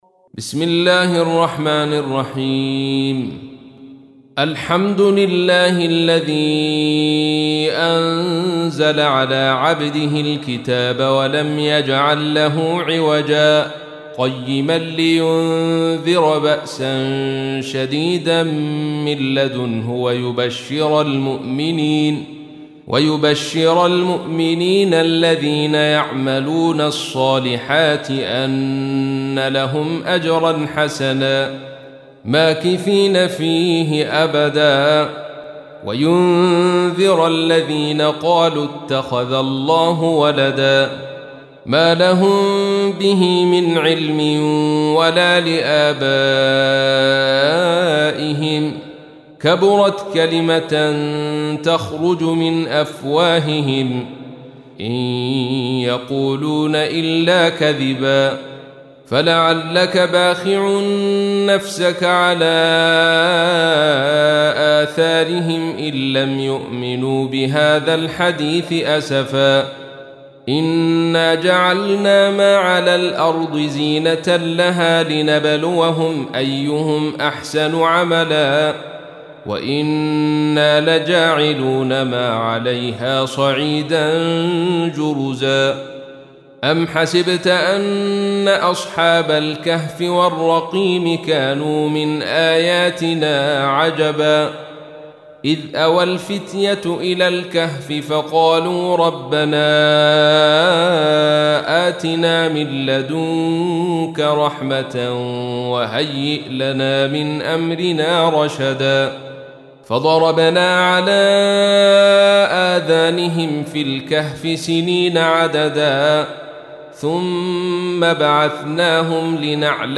تحميل : 18. سورة الكهف / القارئ عبد الرشيد صوفي / القرآن الكريم / موقع يا حسين